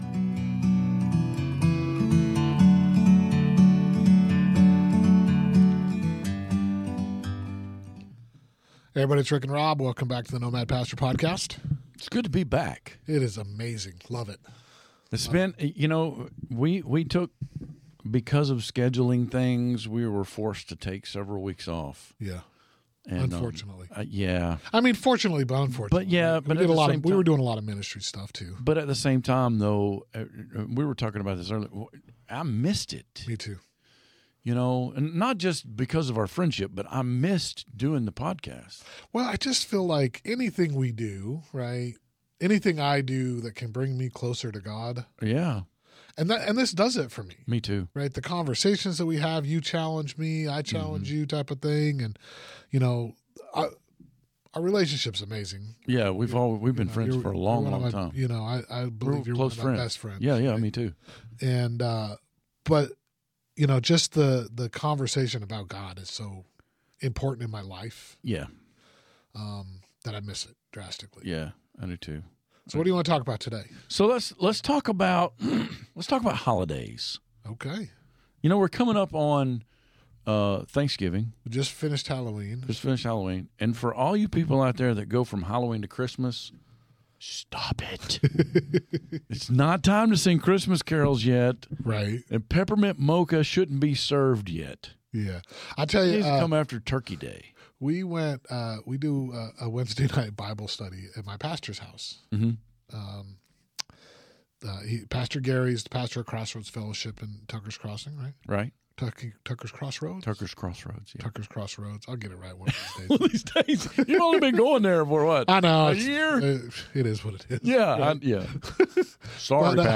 We discussed a couple of different topics around various holidays. The conversation might surprise you but do we agree?